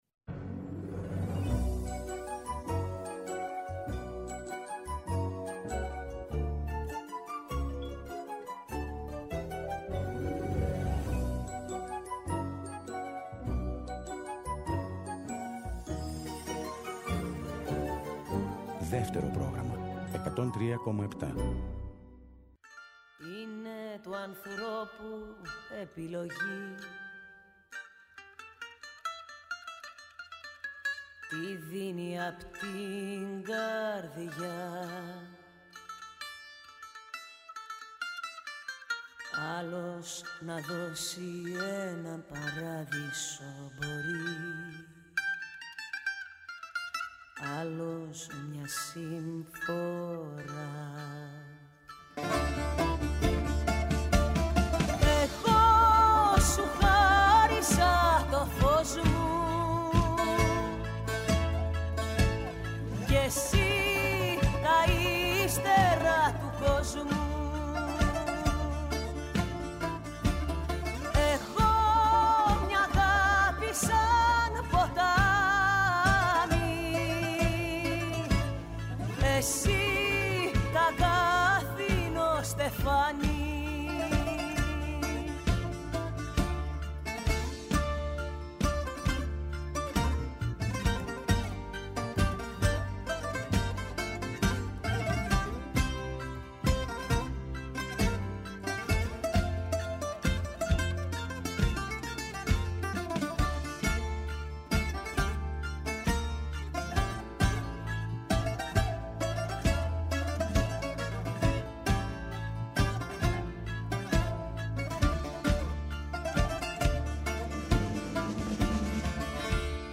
ΔΕΥΤΕΡΟ ΠΡΟΓΡΑΜΜΑ Παντος Καιρου Συνεντεύξεις